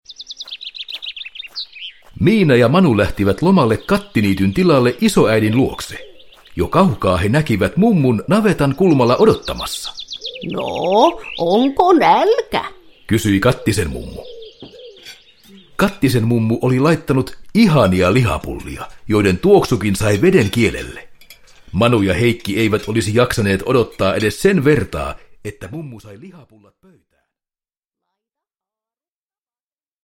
Miina ja Manu keittiössä – Ljudbok – Laddas ner